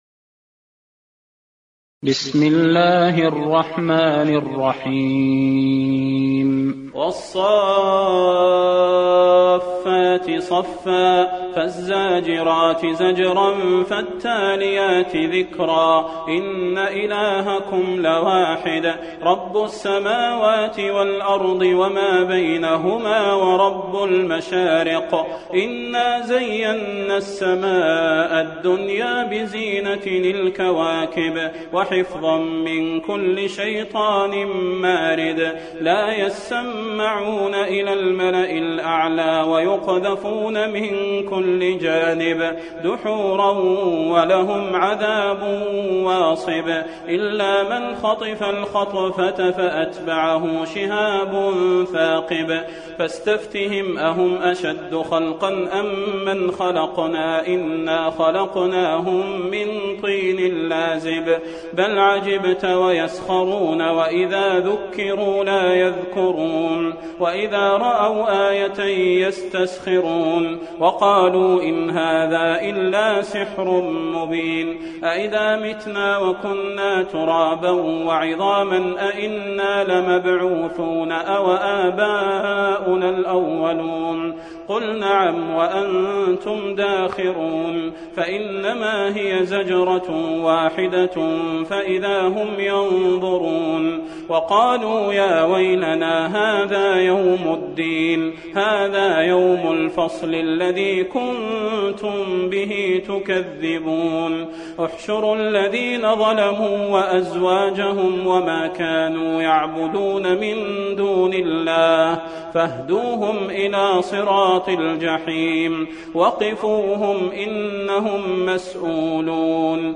المكان: المسجد النبوي الصافات